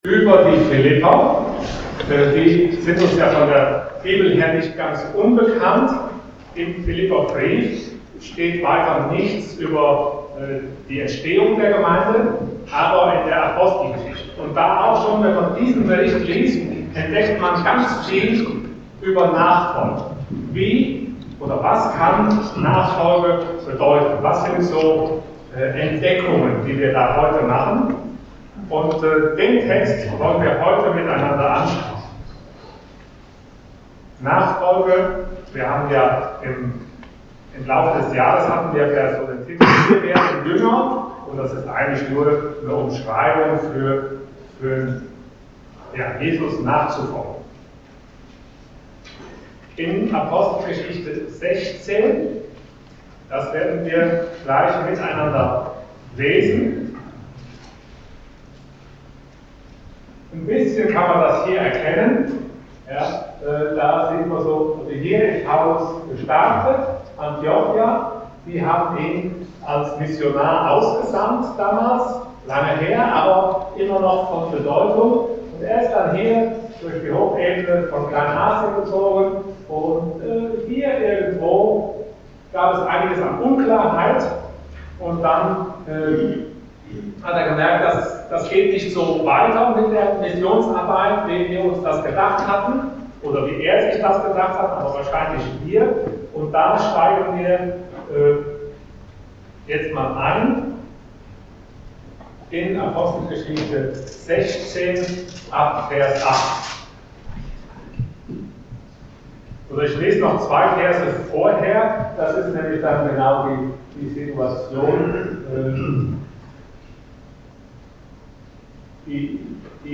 Passage: Acts 16:8-40 Dienstart: Sonntag Morgen %todo_render% Nachfolge entdecken